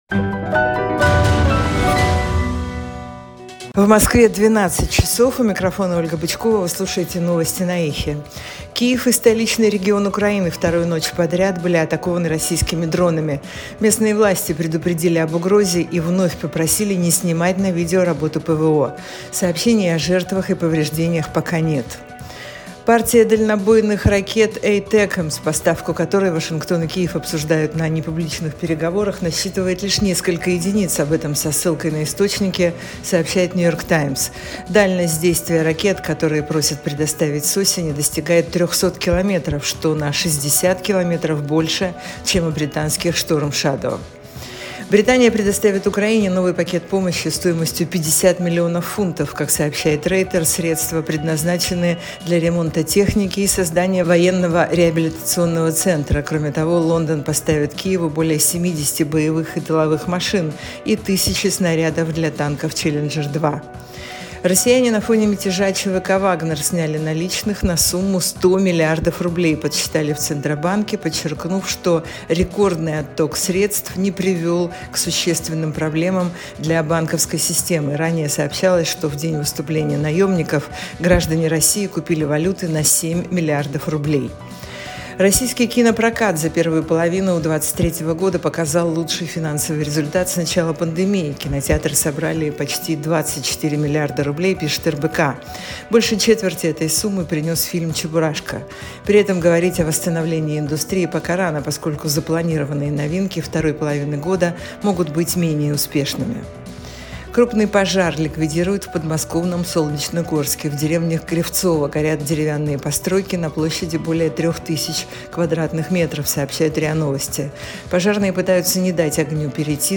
Слушайте свежий выпуск новостей «Эха»
Новости 12:00